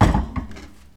place_object.ogg